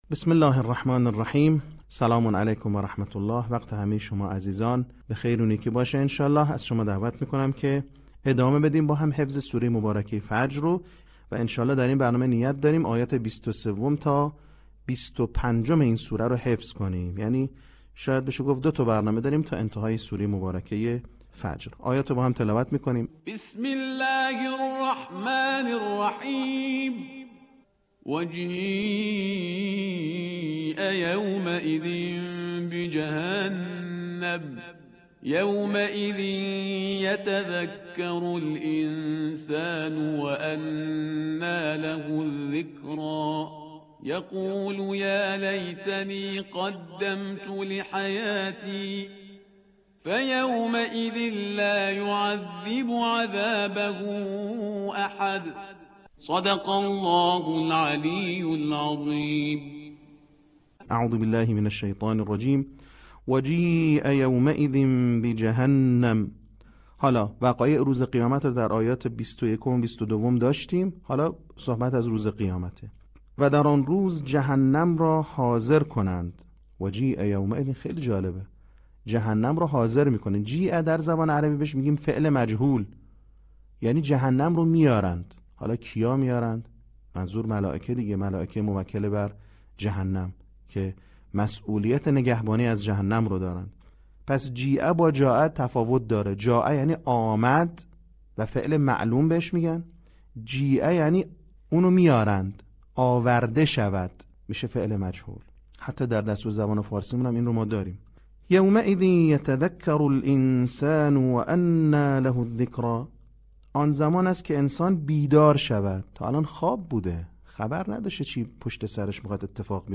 صوت | بخش ششم آموزش حفظ سوره فجر